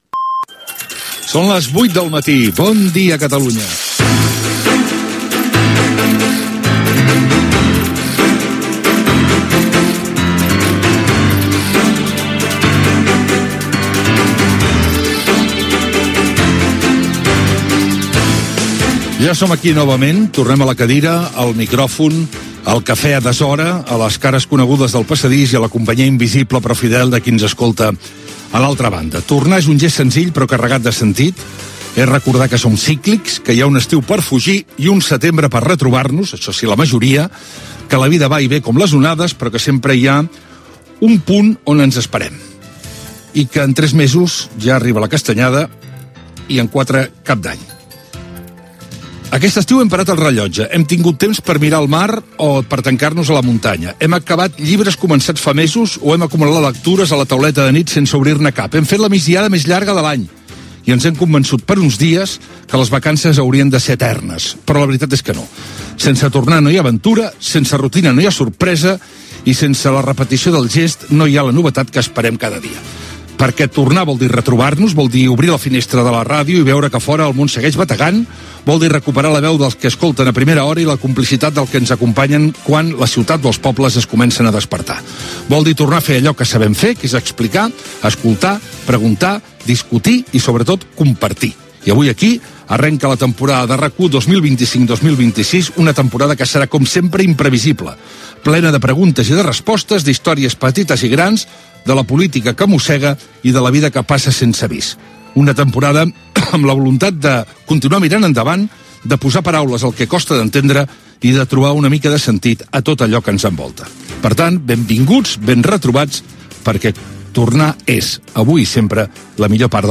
Indicatiu del programa, data, el temps
Info-entreteniment